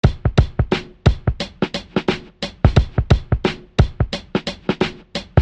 嗡嗡鼓88
Tag: 88 bpm Hip Hop Loops Drum Loops 939.72 KB wav Key : Unknown